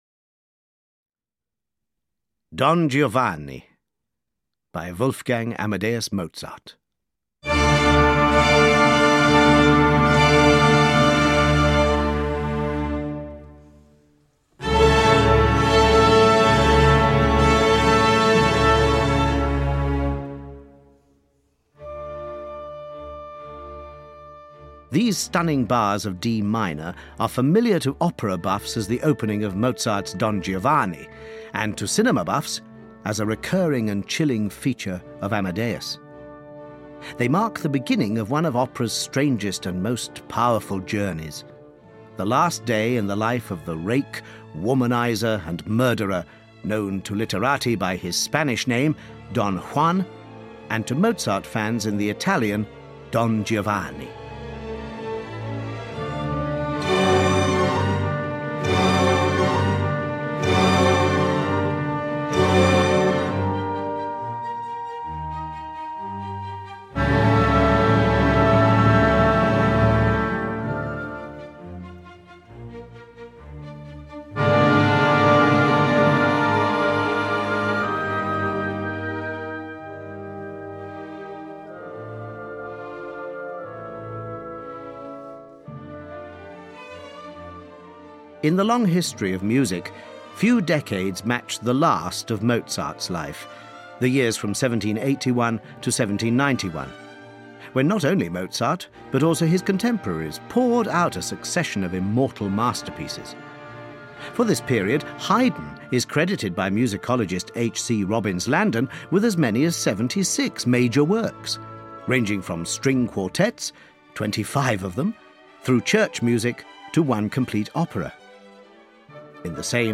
Audio knihaOpera Explained – Don Giovanni (EN)
Ukázka z knihy